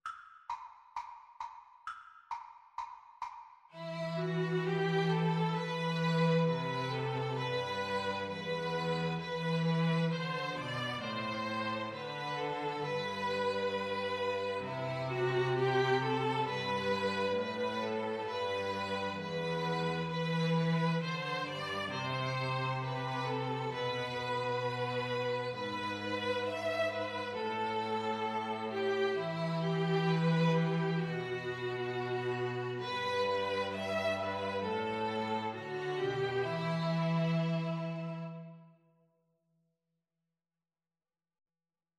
Violin 1Violin 2Cello
4/4 (View more 4/4 Music)